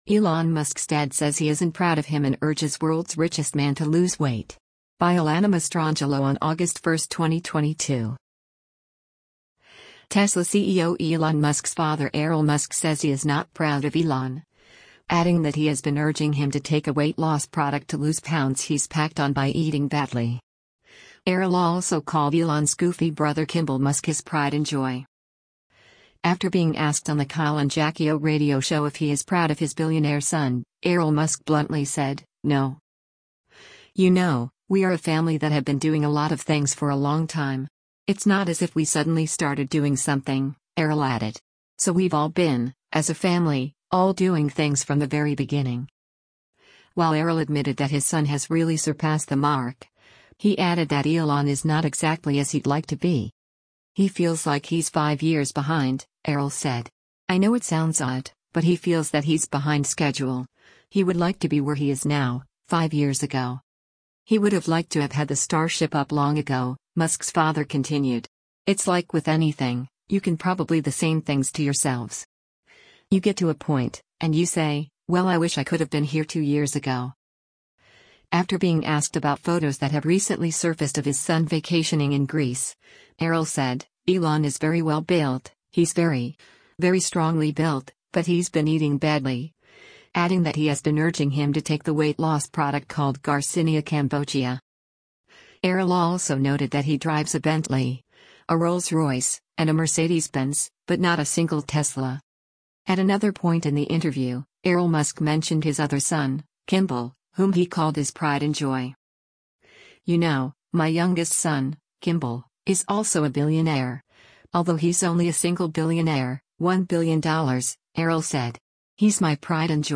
After being asked on the Kyle and Jackie O radio show if he is proud of his billionaire son, Errol Musk bluntly said, “No.”